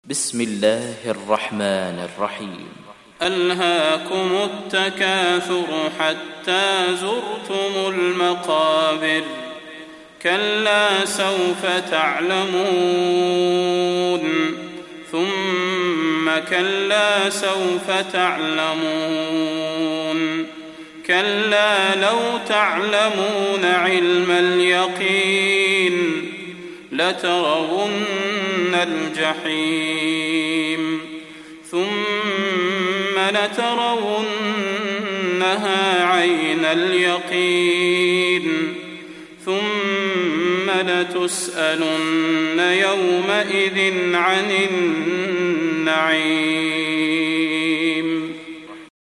تحميل سورة التكاثر mp3 بصوت صلاح البدير برواية حفص عن عاصم, تحميل استماع القرآن الكريم على الجوال mp3 كاملا بروابط مباشرة وسريعة